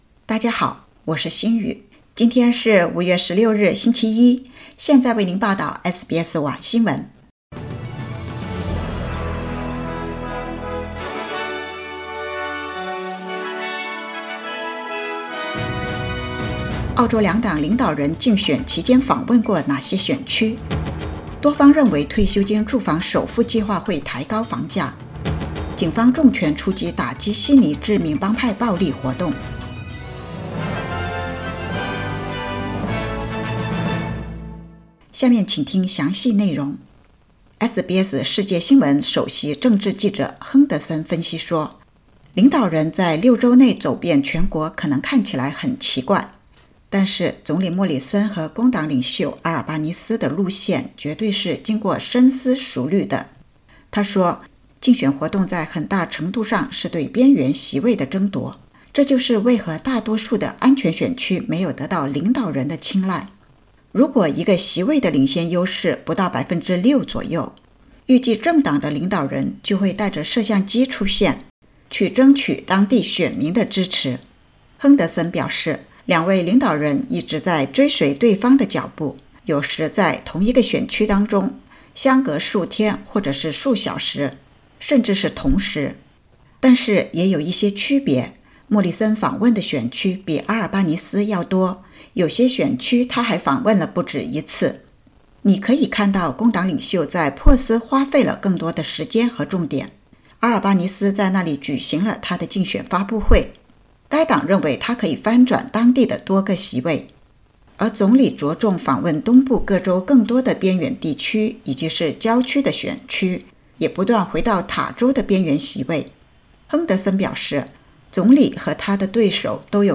SBS晚新闻（2022年5月16日）